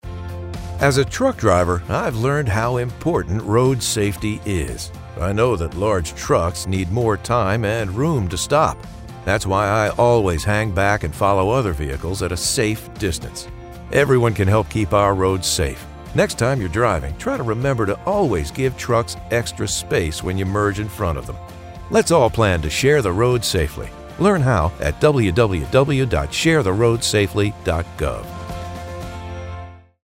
Audio Public Service Announcements (PSAs)